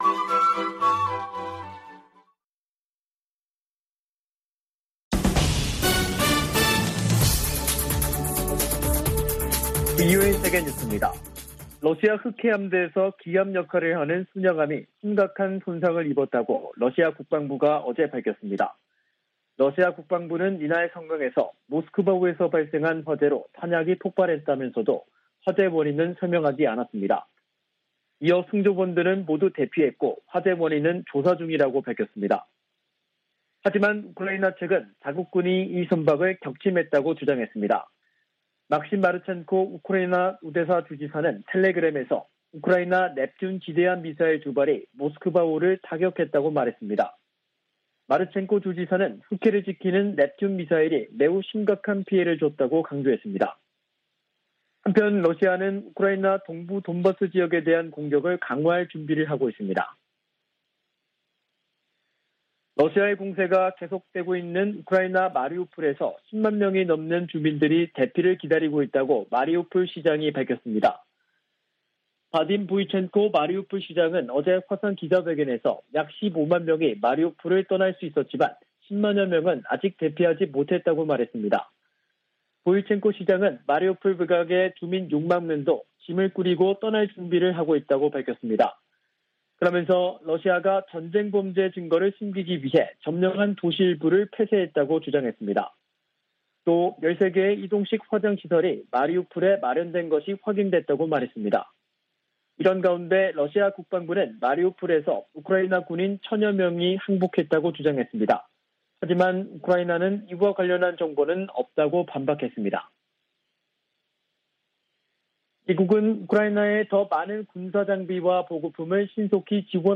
VOA 한국어 간판 뉴스 프로그램 '뉴스 투데이', 2022년 4월 14일 2부 방송입니다. 미 7함대는 에이브러햄 링컨 항모가 동해에서 일본 자위대와 연합훈련을 실시하고 있다며 북한에 적대적인 의도는 없다고 밝혔습니다. 미 상·하원이 중국 견제 법안에 관한 조율 절차에 들어갔습니다.